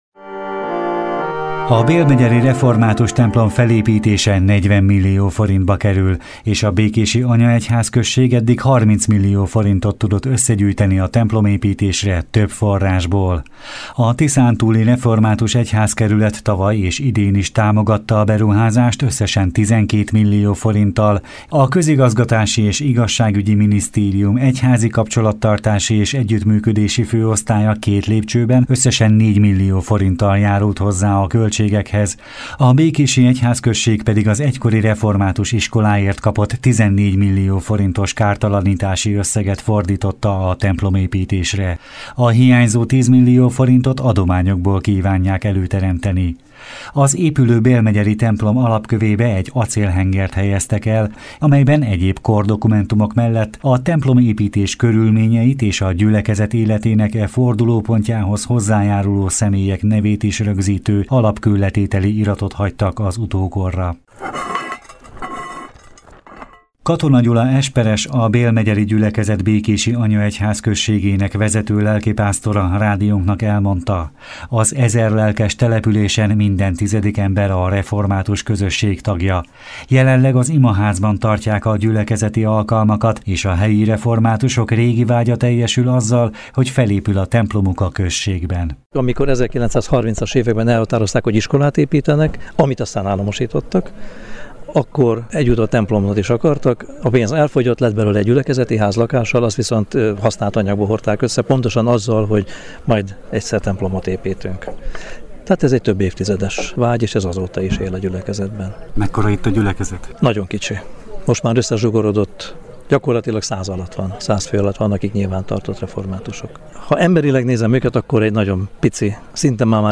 A bélmegyeri református templom alapkőletétele alkalmából tartott ünnepi istentiszteleten Bölcskei Gusztáv, a Tiszántúli Református Egyházkerület püspöke hirdetett igét, aki a gazdasági és értékrendi válságra utalva azt mondta: az Isten ezekben a nehéz időkben templomépítéshez ad indíttatást a gyülekezeteknek és a lelkipásztoroknak, ami bizonyság arra, hogy az ige él és hat a vallási közösségekre.
belmegyeri-templom-alapkoletetel.mp3